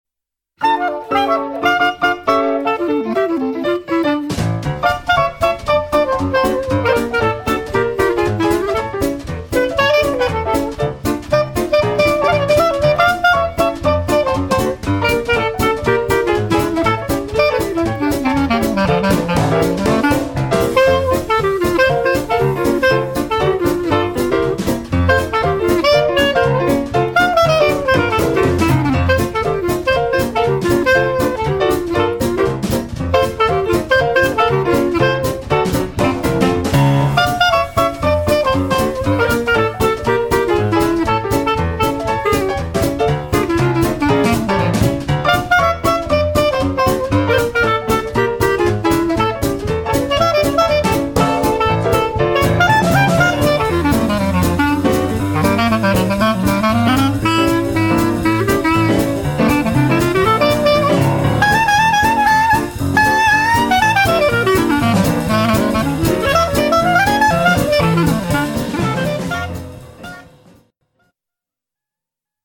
clarinette
piano
batterie